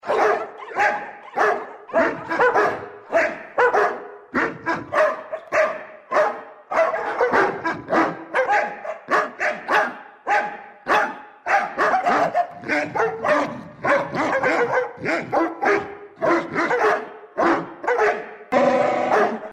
Собаки Поют